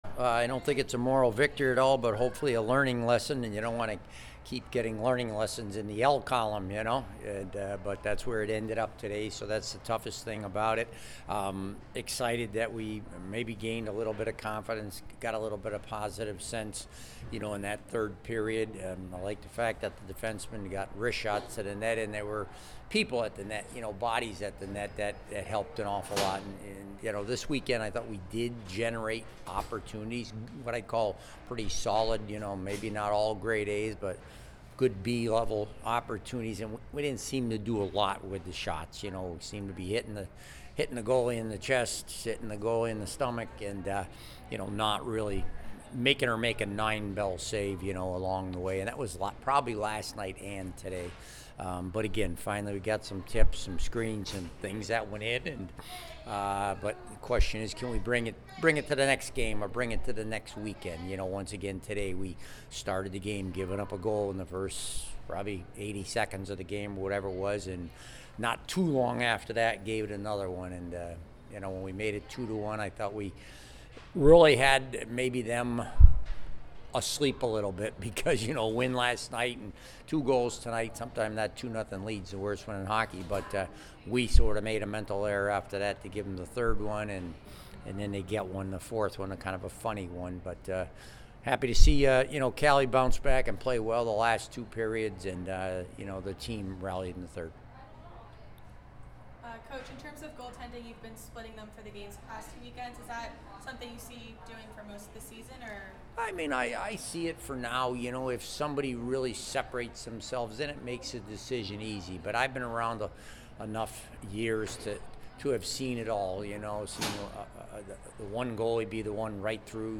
BC Postgame Interviews